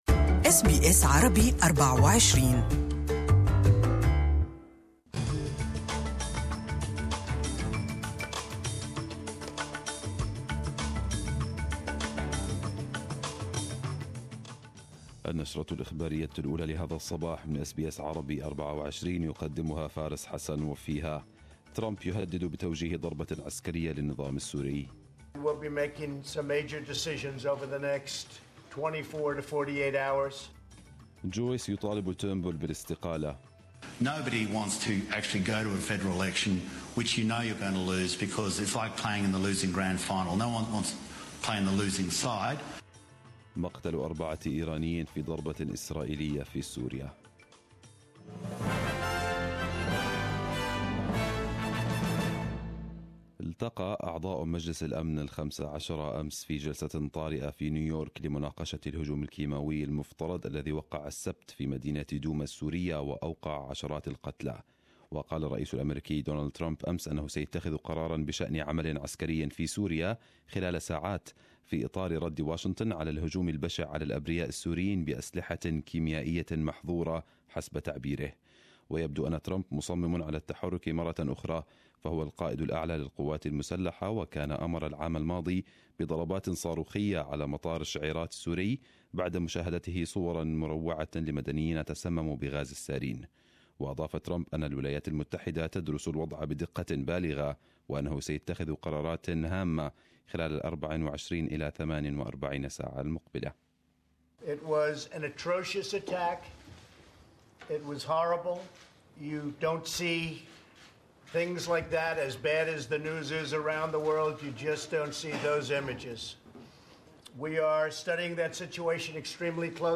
Arabic News Bulletin 10/04/2018